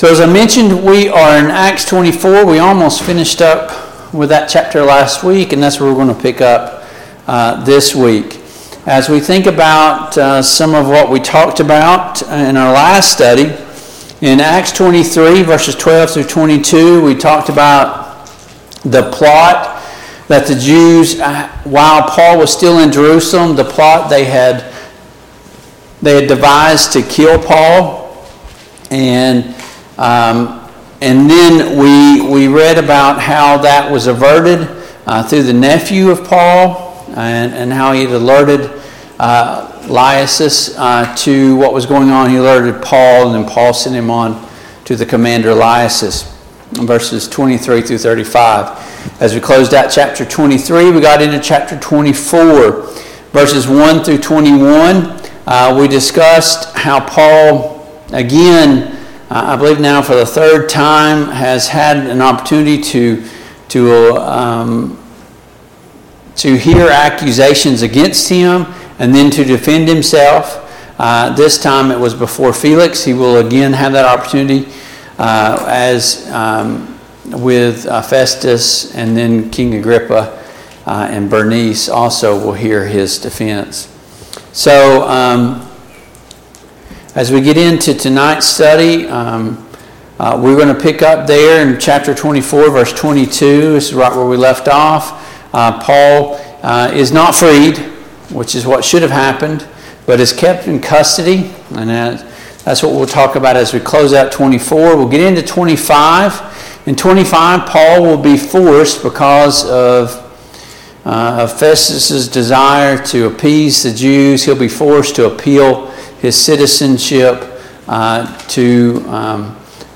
Passage: Acts 24:22-27; Acts 25:1-12 Service Type: Mid-Week Bible Study